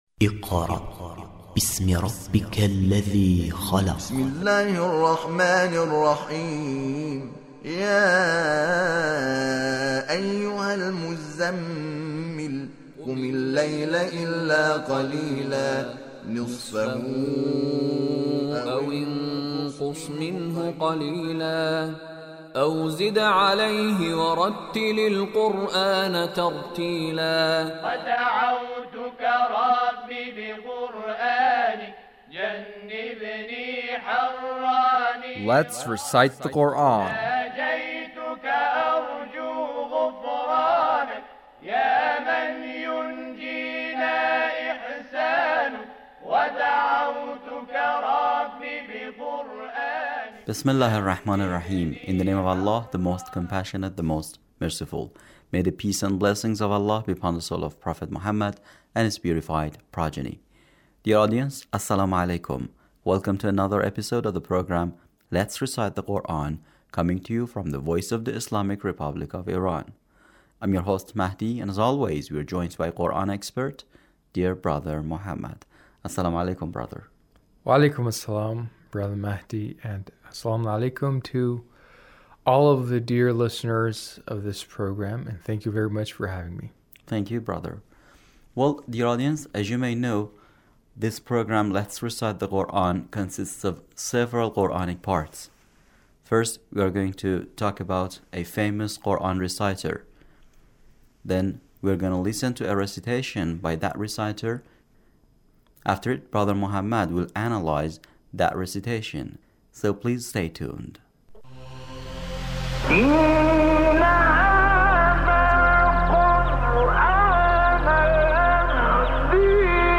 Recitation of Sheikh Shaban Sayyad